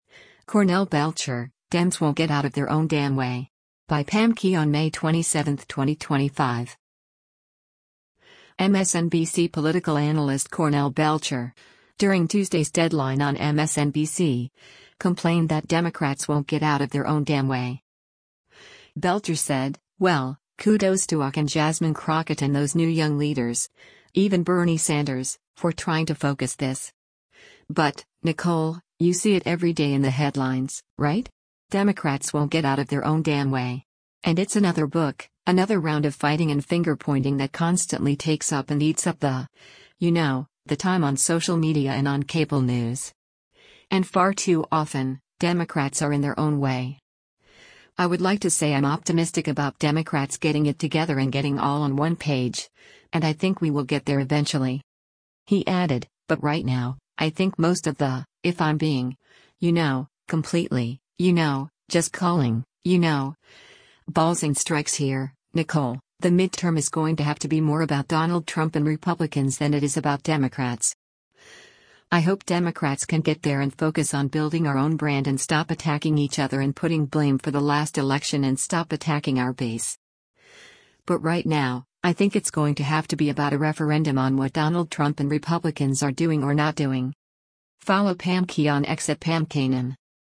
MSNBC political analyst Cornell Belcher, during Tuesday’s “Deadline” on MSNBC, complained that “Democrats won’t get out of their own damn way.”